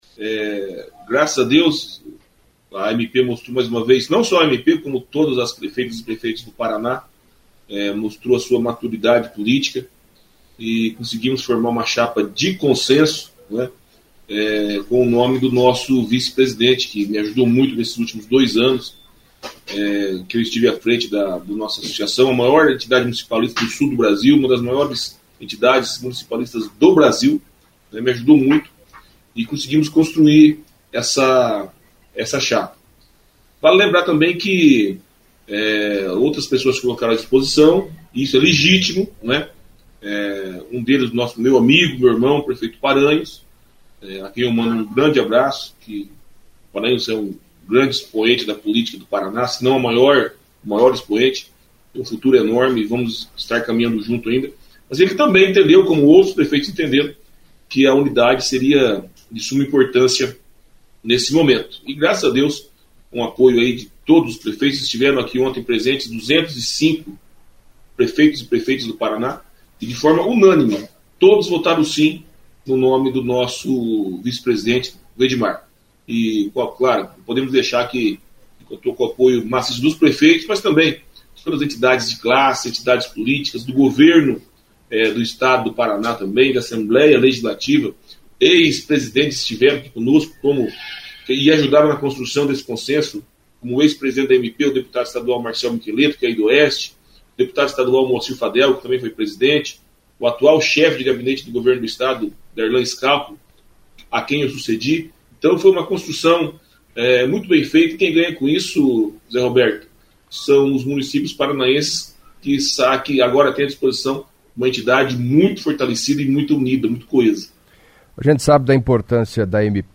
Em entrevista à CBN Cascavel nesta terça-feira (21) o prefeito de Jesuítas, Júnior Weiller, falou da eleição realizada nesta segunda (20), que definiu o prefeito de Santa Cecília do Pavão, Norte Pioneiro, Edimar Aparecido Pereira dos Santos, como presidente da AMP, pelos próximos dois anos. O processo de escolha contou com chapa única, houve consenso, e ocorreu na sede da entidade, centro de Curitiba.